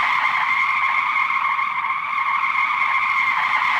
Index of /controlearduino/Assets/Standard Assets/Vehicles/Car/Audio
Skid.wav